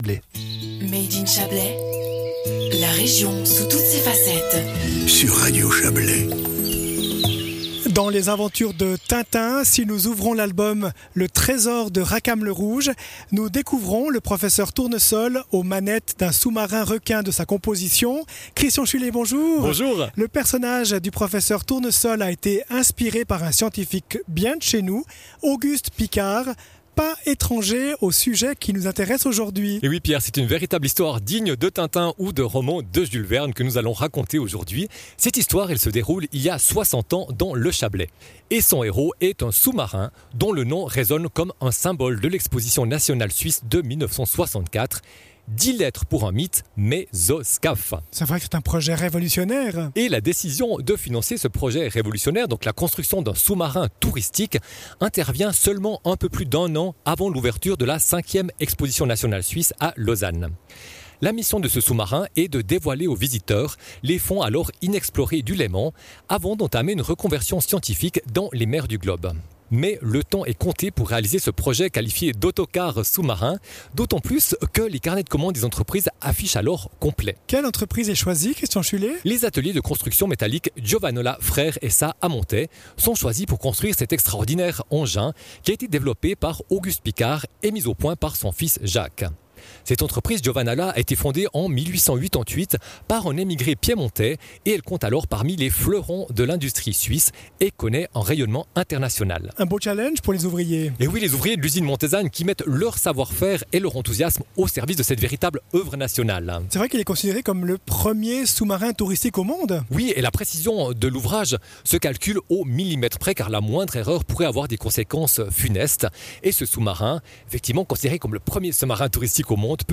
historien